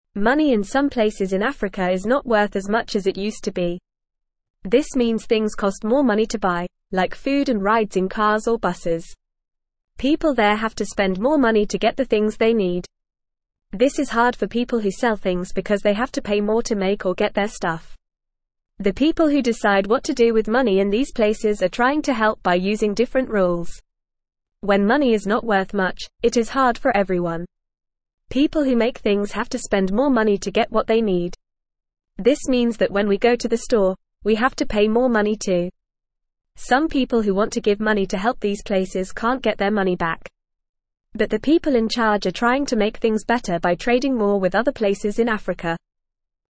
Fast
English-Newsroom-Beginner-FAST-Reading-Money-Worth-Less-in-Africa-People-Struggling-to-Buy.mp3